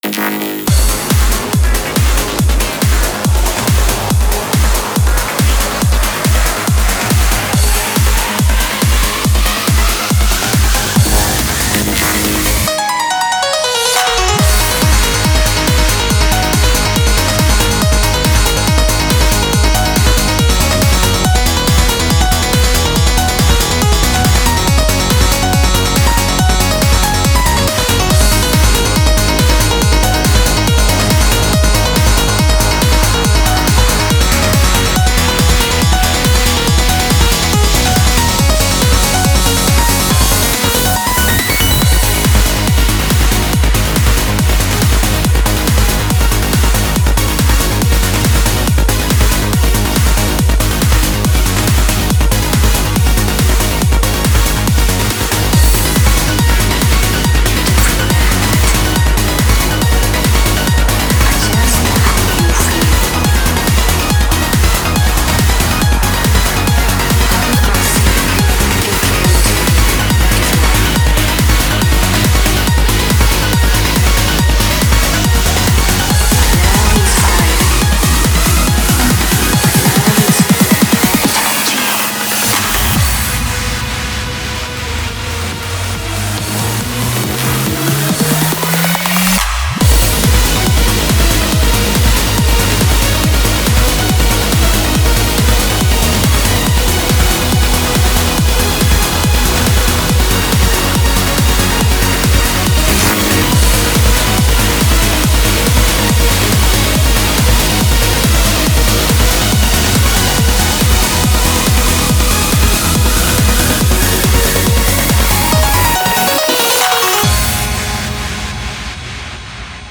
BPM70-140
Audio QualityPerfect (High Quality)
Comentarios[TRANCE REWORK]